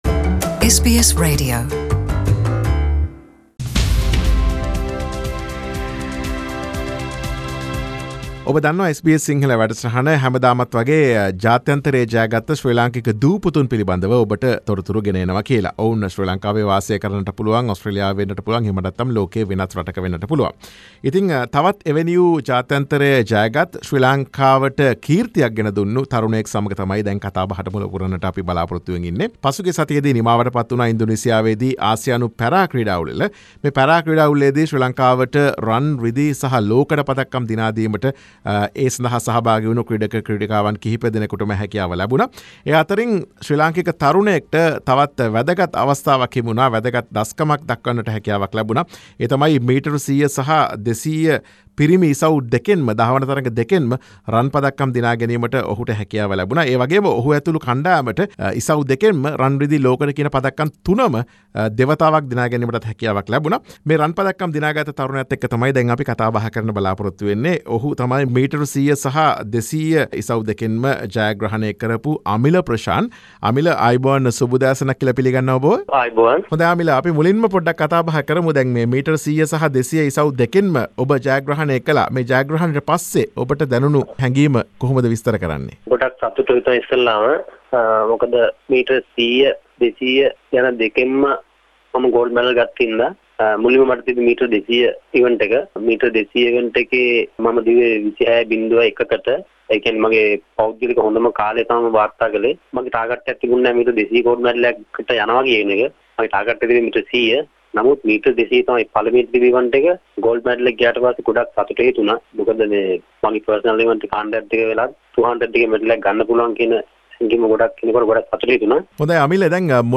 තම ජයග්‍රහණ සහ ක්‍රීඩා දිවිය පිළිබඳ SBS සිංහල ගුවන් විදුලිය සමග සිදුකළ සාකච්ඡාව.